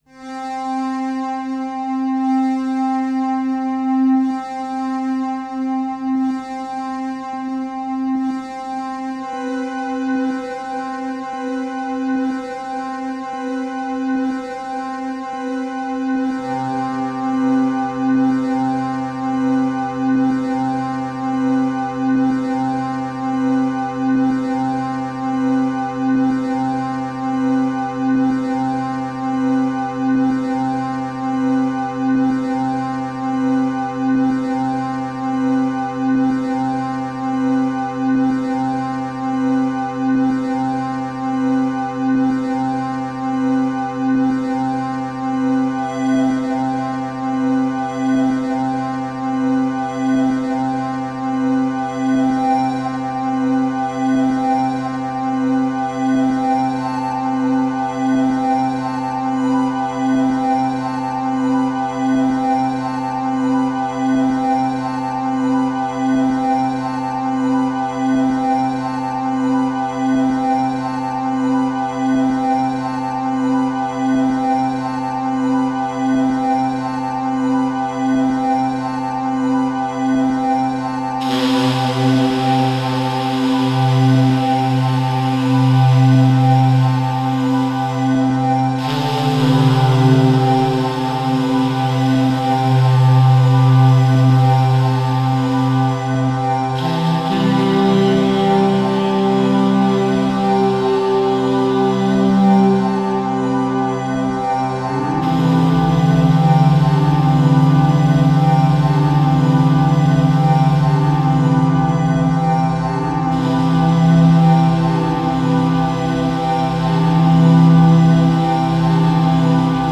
guitar and other sounds
saxophone